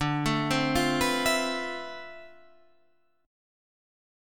Dm13 Chord
Listen to Dm13 strummed